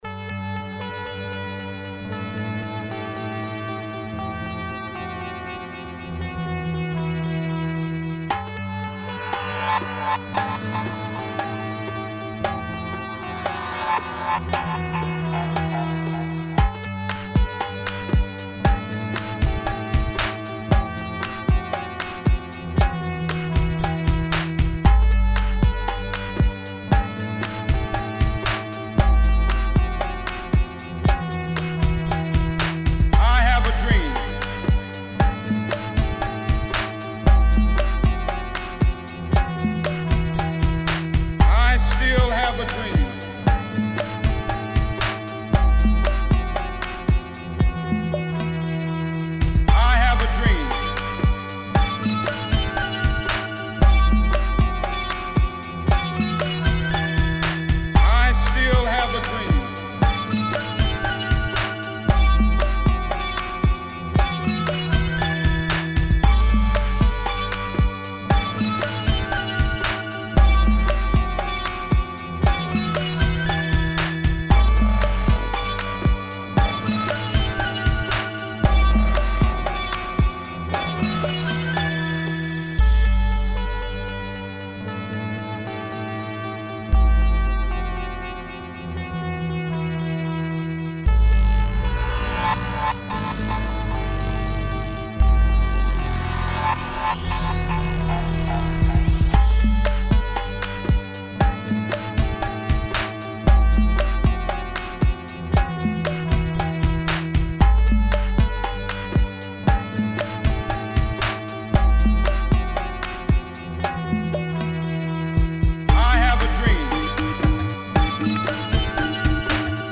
I synth di questa canzone sono tutti suonati con un C64 e filtrati con un multieffetto per chitarra Alesis Quadraverb GT.
direi....lenta e inquietante
Probabilmente con un po' piu' di movimento e varieta' poteva piacermi, chi lo sa.